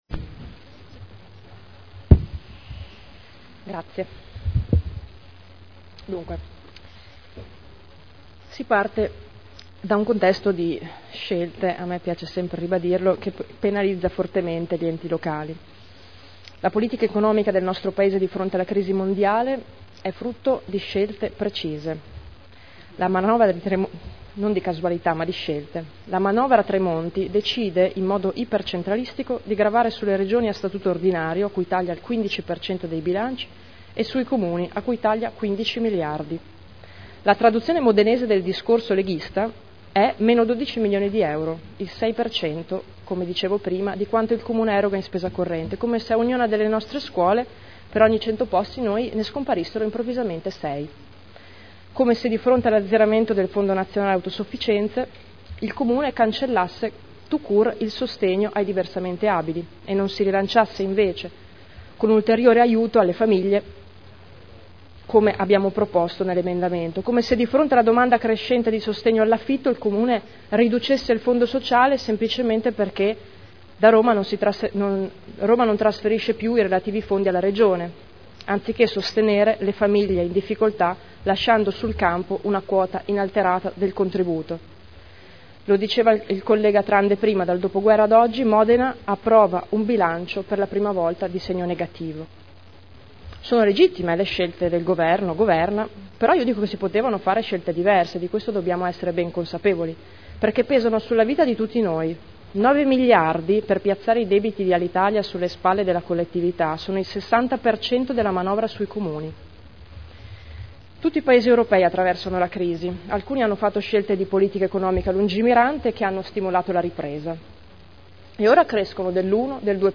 Seduta del 28/03/2011. Dibattito sul Bilancio.